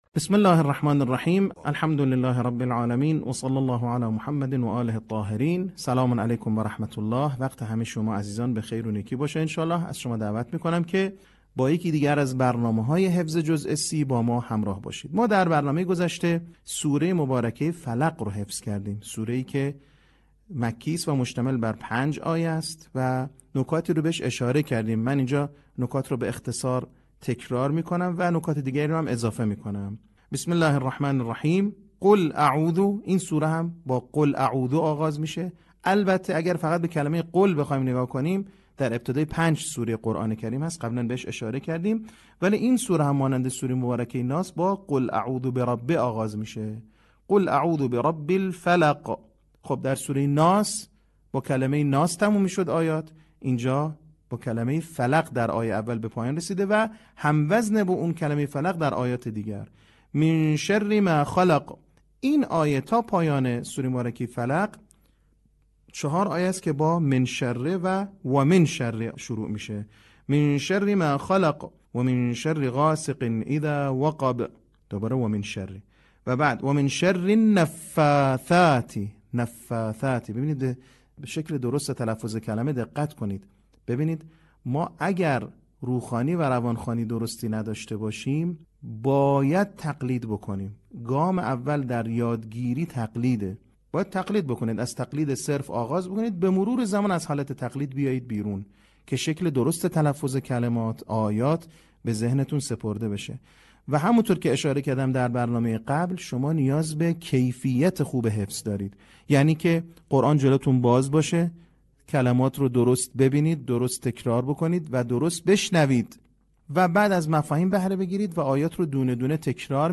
صوت | نکات آموزشی حفظ سوره فلق
به همین منظور مجموعه آموزشی شنیداری (صوتی) قرآنی را گردآوری و برای علاقه‌مندان بازنشر می‌کند.